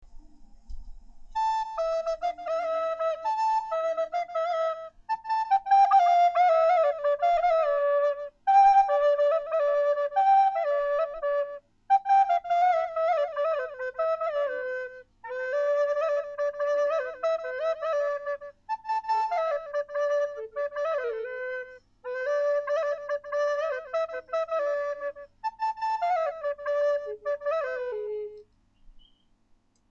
חליל אלט:אני
כרגיל-הוקלט במיקרופון ביתי
ניגון שמח ומדהים בלי זיופים.